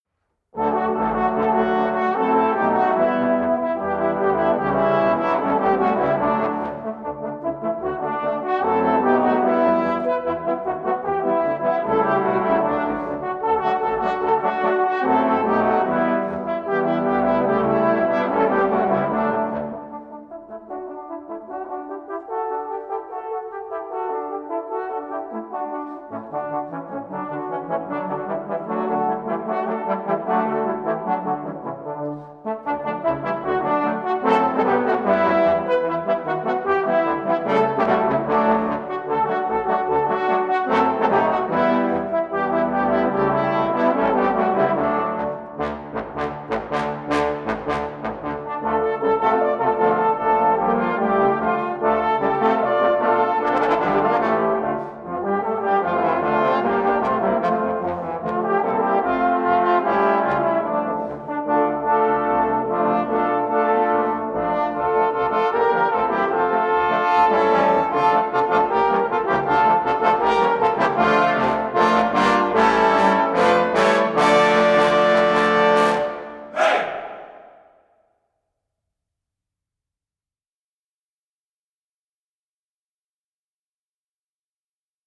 This fun arrangement